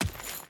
Dirt Chain Run 1.ogg